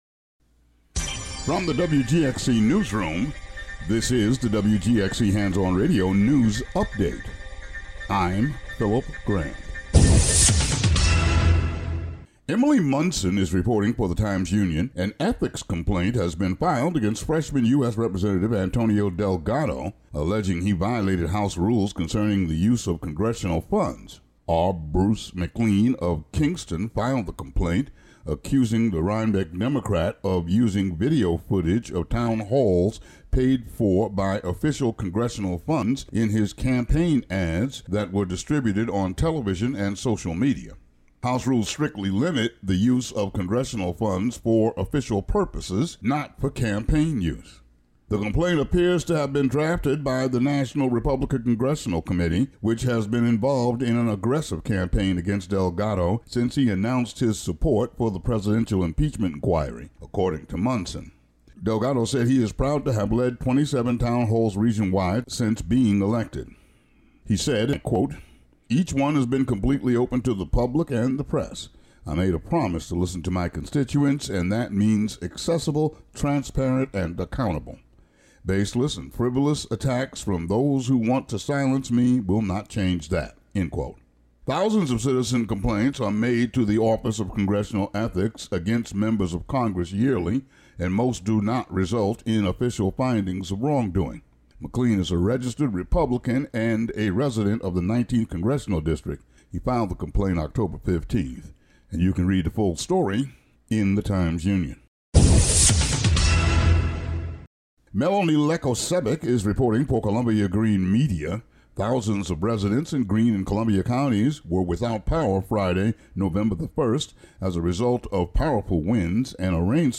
Local news update.